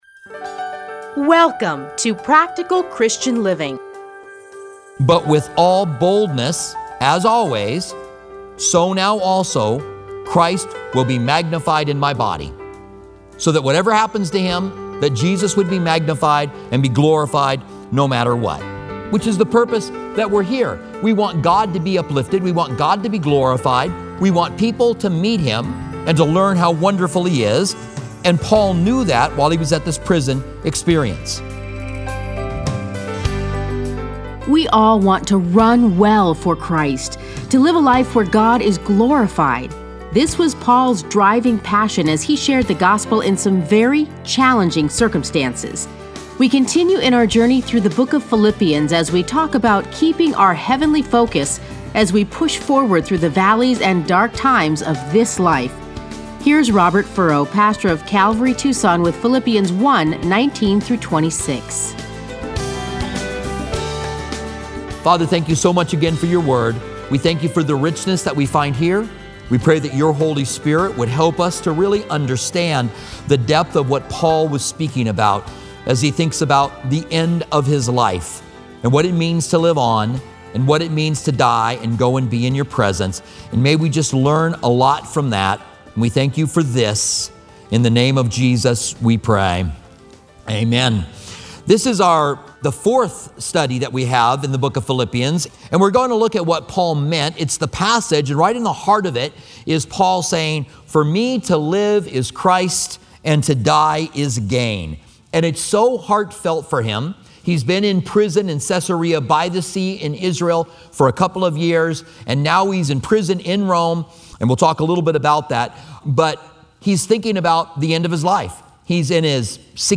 Listen to a teaching from Philippians 1:19-26.